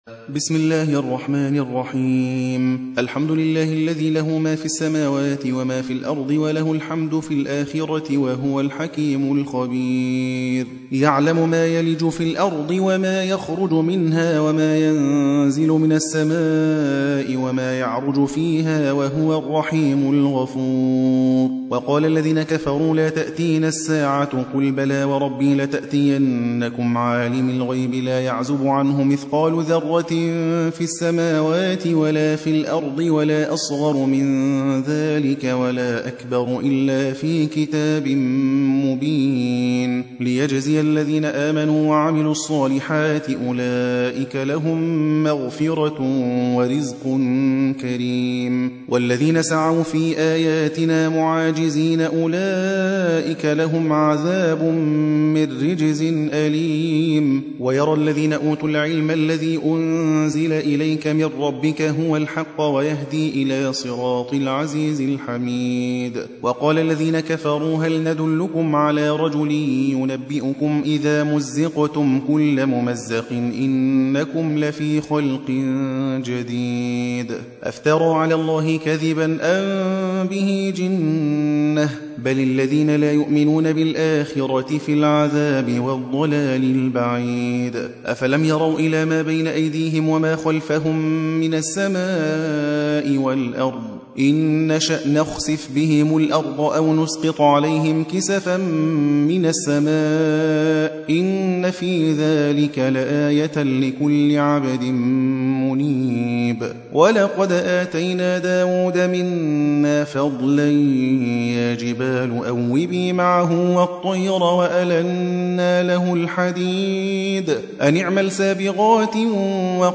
34. سورة سبأ / القارئ